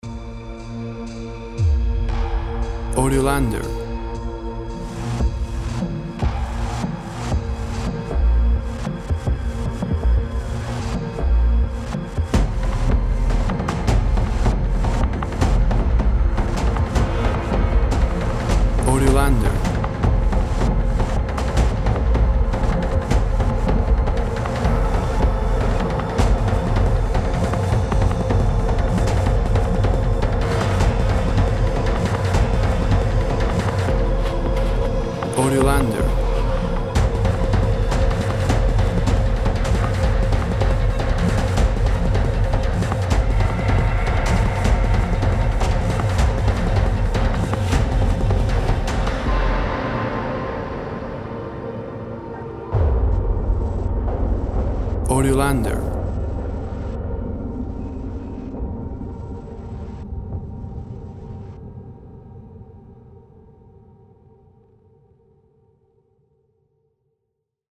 Tempo (BPM) 156